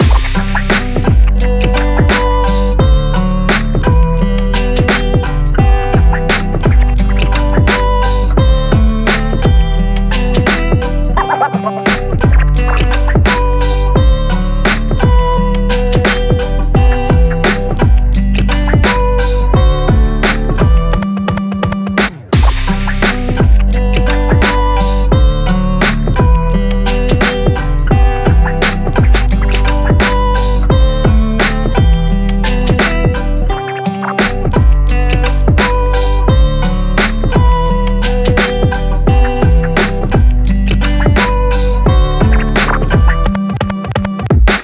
BPM86、Em/Gmaj、同じドラムセット縛りの6パターンのビート・トラックです。
EMO HIPHOP LOOP TRACK BPM86 Em/Gmaj pattern B